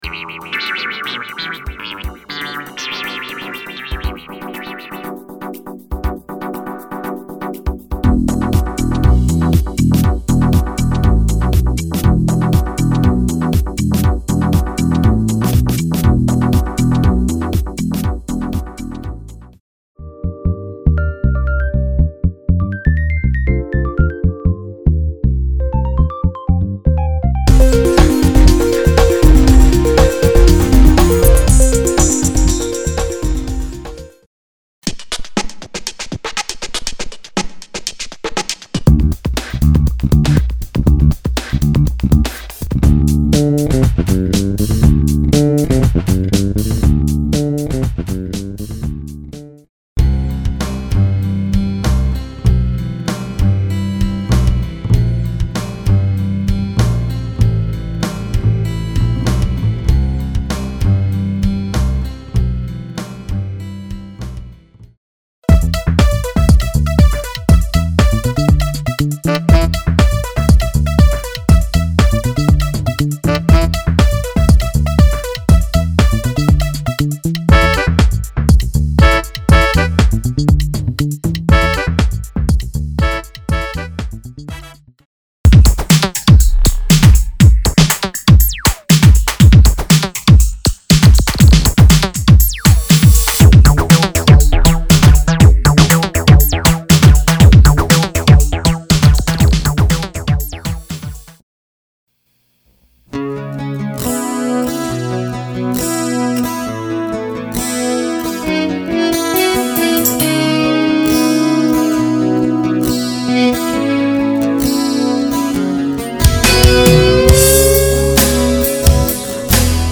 Custom Music Demo